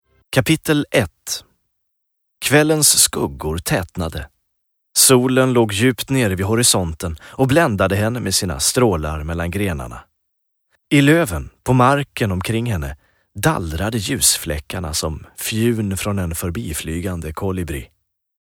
Ljudböcker & e-tidningar